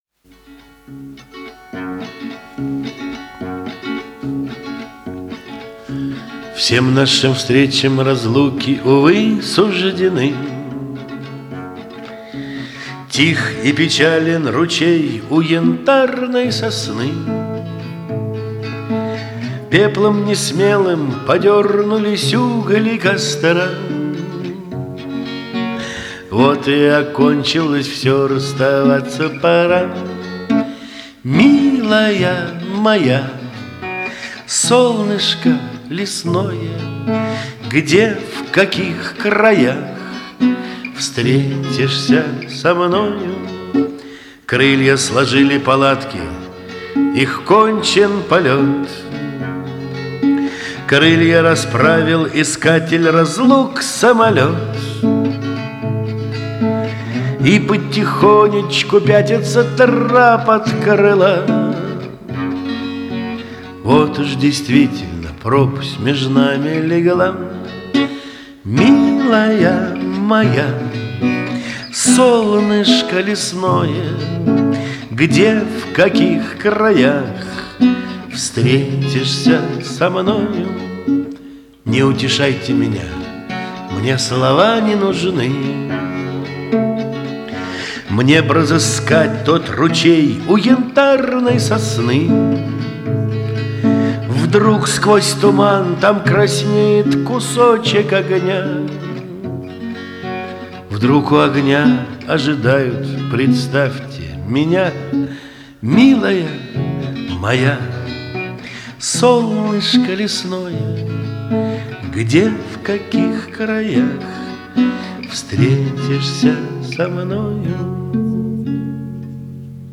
Бард